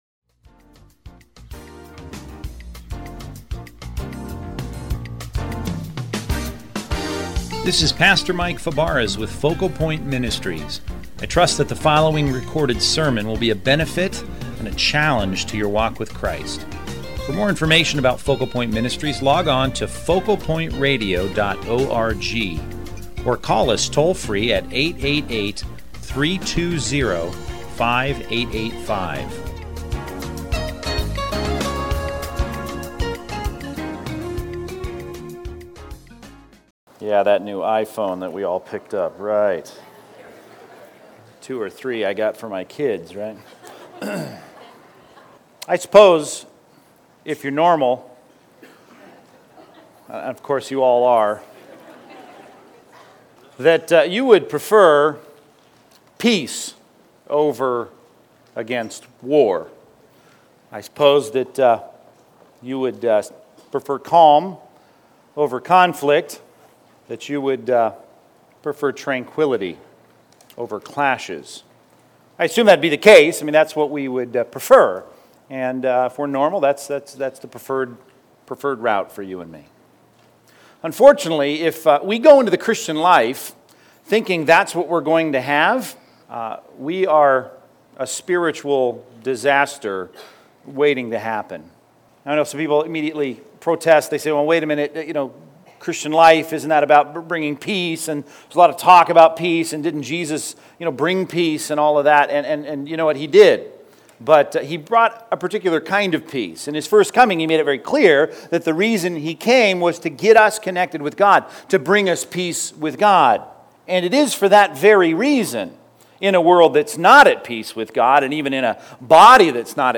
Category: Sermons